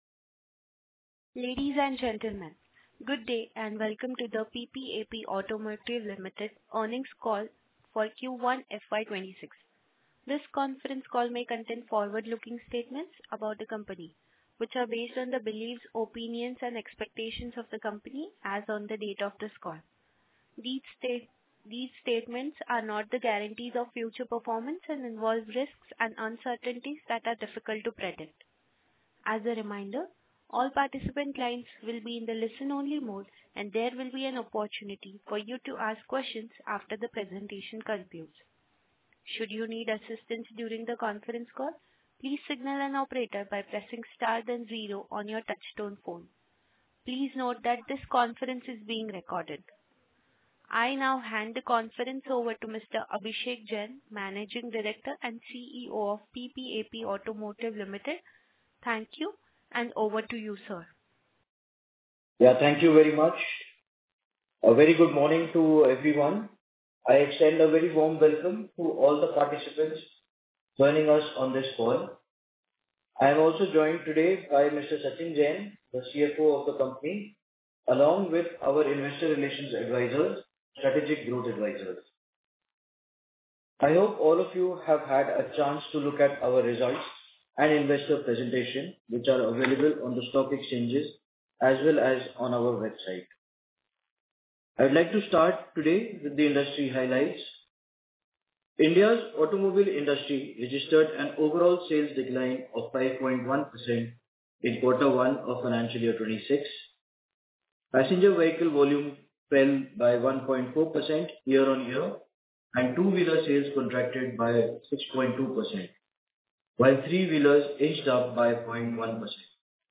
Concalls
Conference_call_recording-Q1FY26.mp3